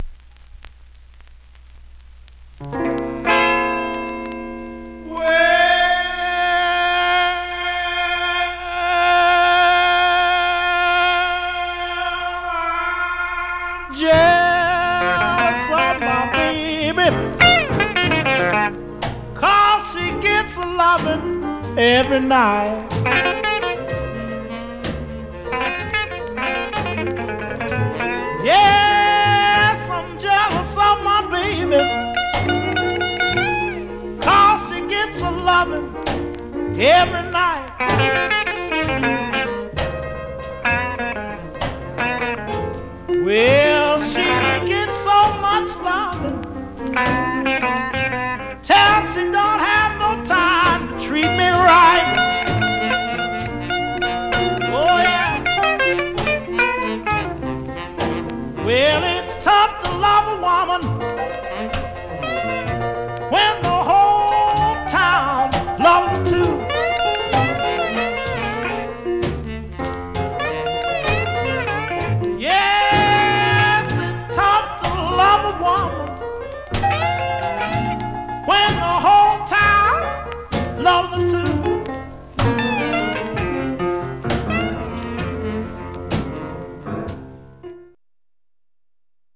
Surface noise/wear
Mono
Rythm and Blues